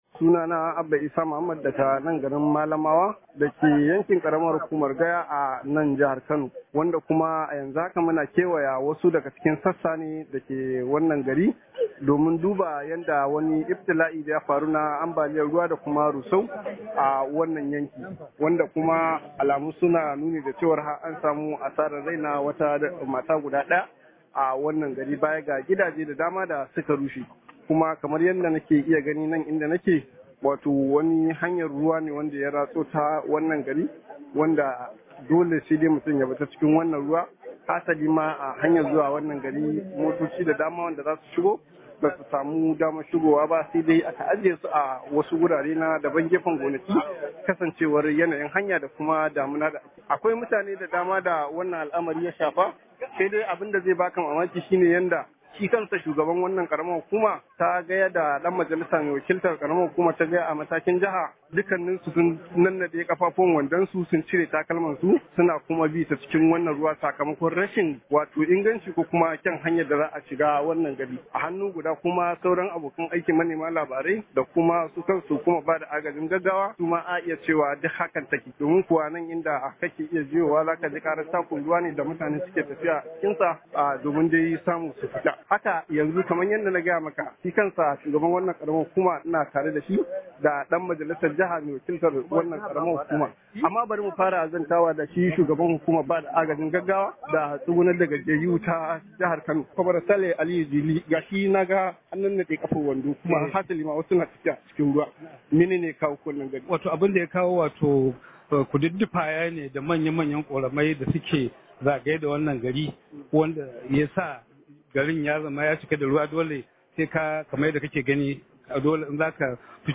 Rahoto: Ganduje ya kai tallafi garin da su ka samu ambaliyar ruwa